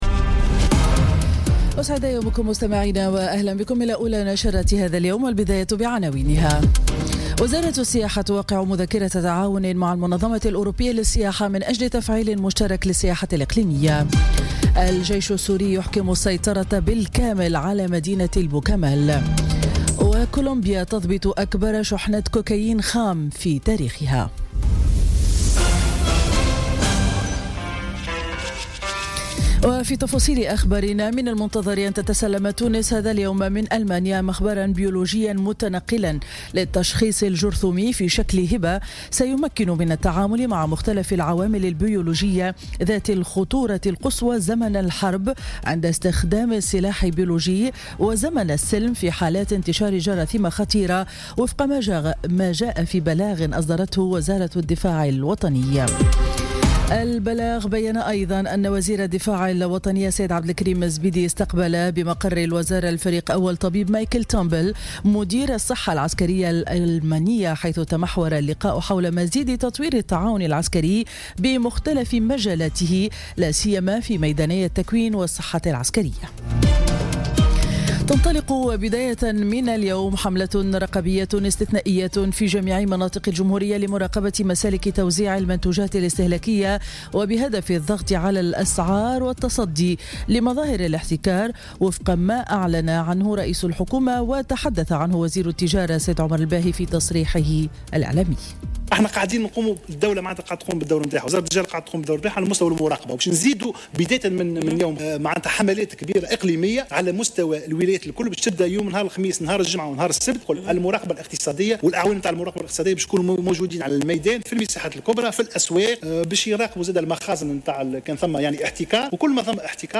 نشرة أخبار السابعة صباحا ليوم الخميس 9 نوفمبر 2017